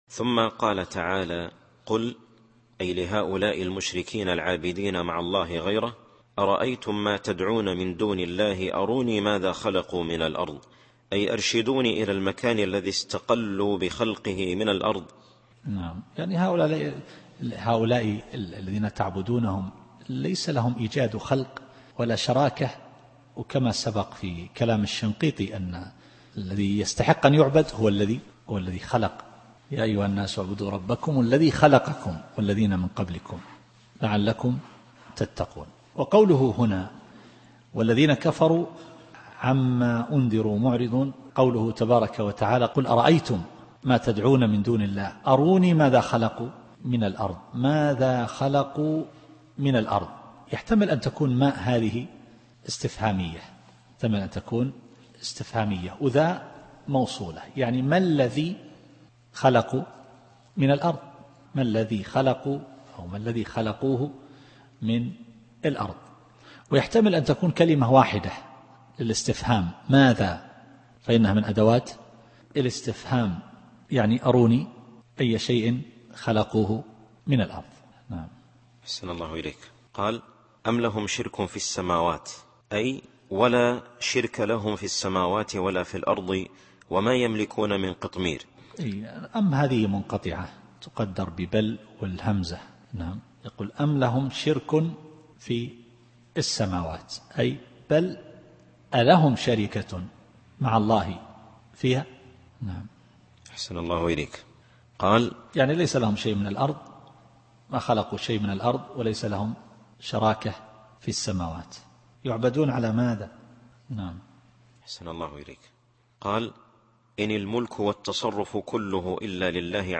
التفسير الصوتي [الأحقاف / 4]